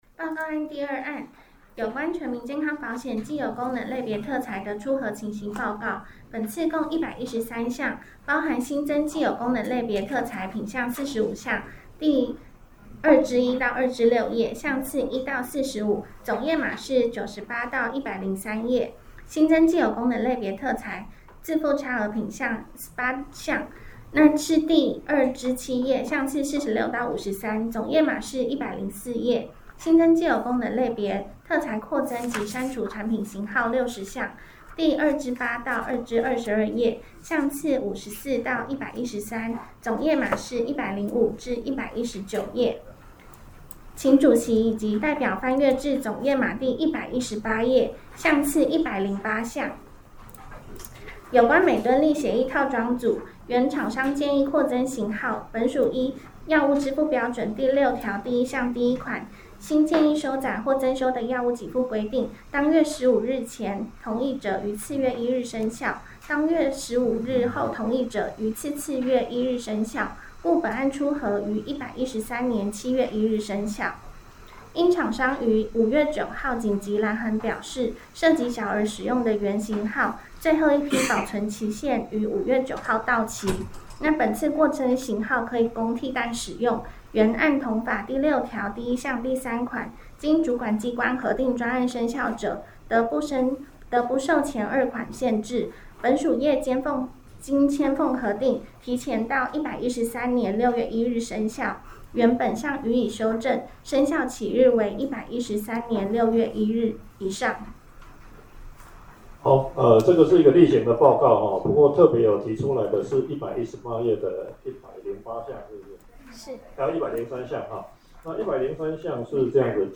會議實錄錄音檔-113年5月